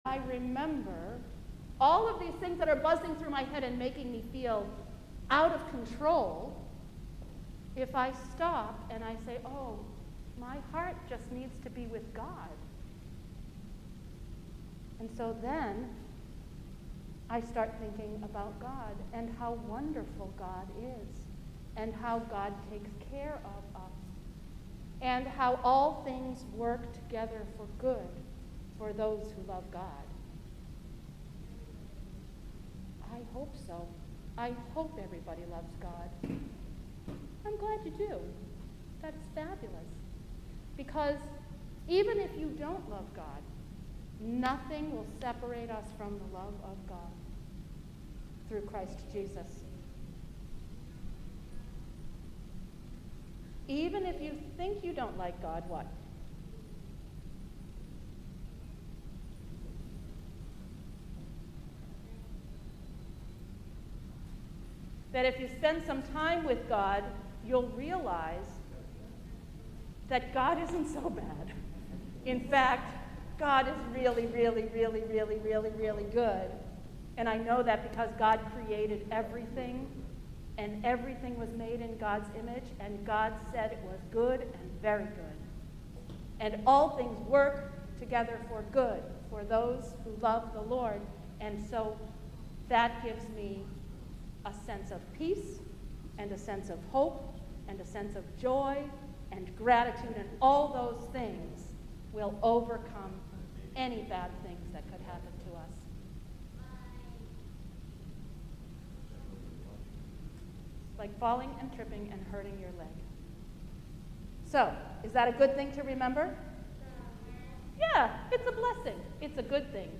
Sermon
during Service of Remembrance and Lament